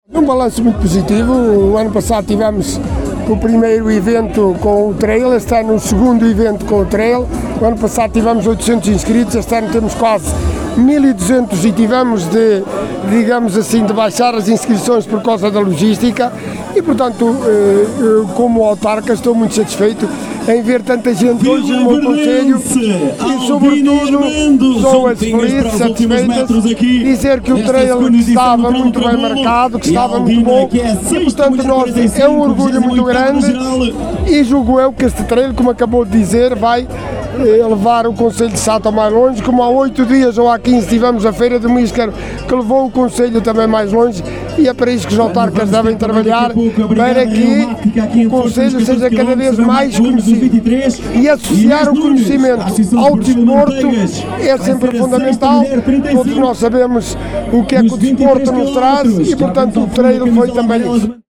Alexandre Vaz, Presidente do Município de Sátão, que participou na caminhada, em declarações à Alive FM, fez um balanço muito positivo deste evento desportivo, “um evento que vai levar o concelho de Sátão mais longe…”.